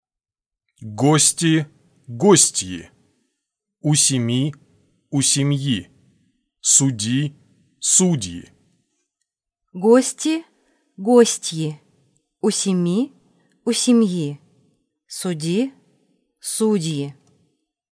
Minimal pairs of words - the first word in each pair has a soft consonant, its counterpart has a soft sign followed by a vowel.